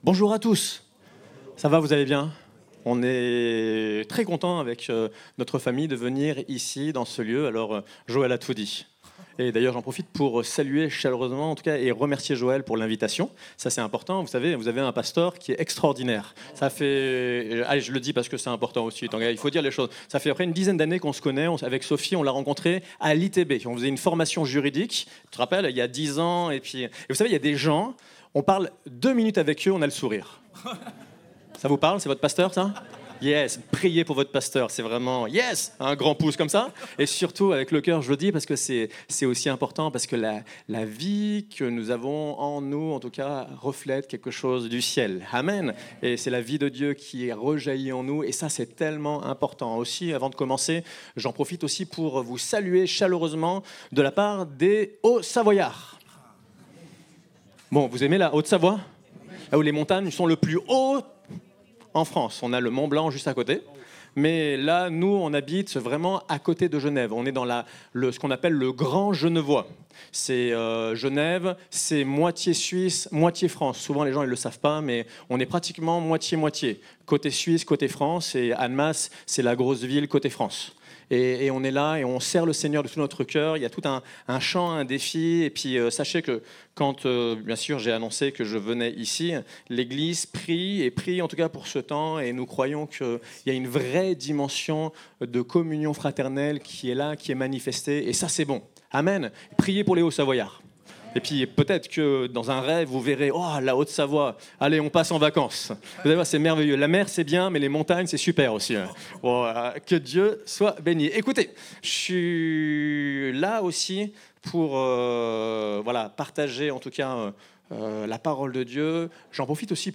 Date : 27 octobre 2024 (Culte Dominical)